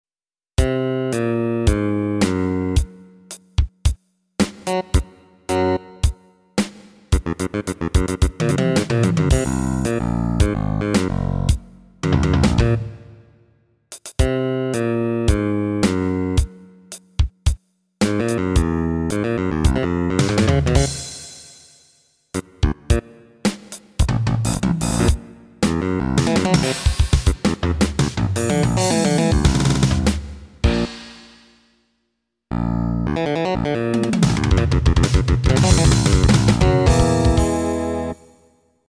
Originally a MIDI file from September, 1999, this is a bass and drum duet in a mostly abtract Zappa-esque style.